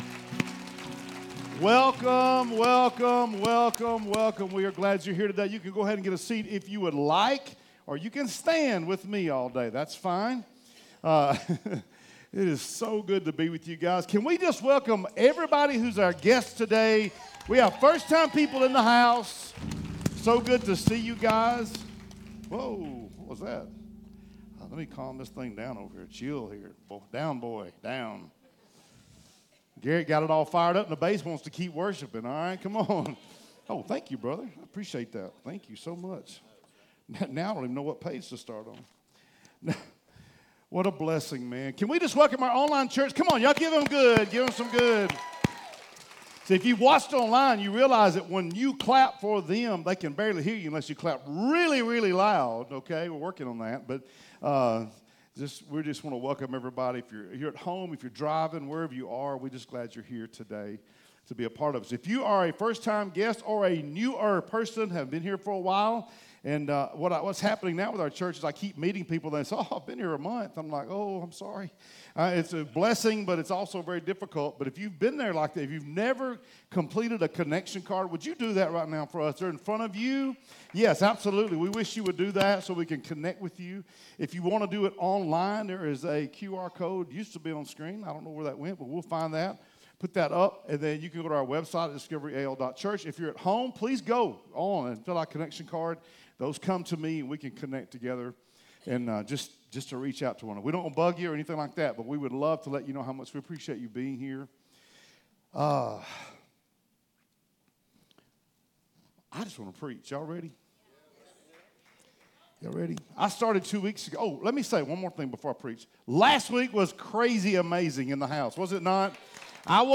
Sermons | Discovery Church